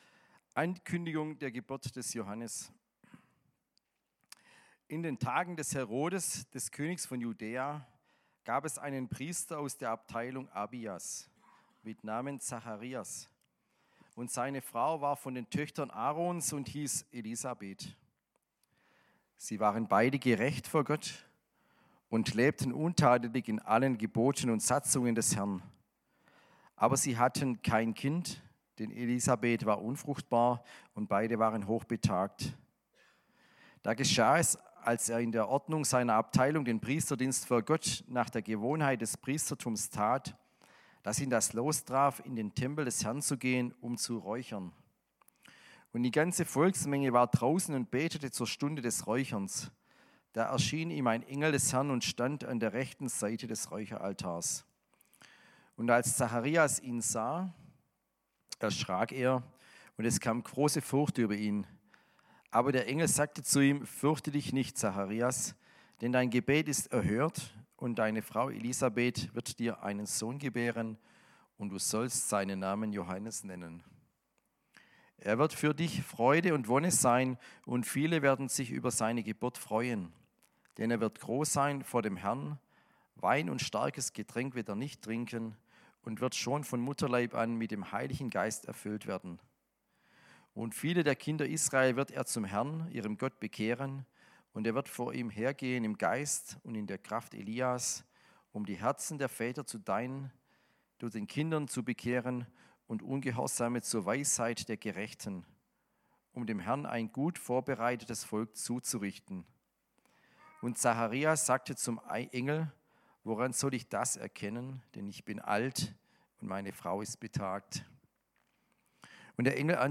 Predigt am 07.12.2025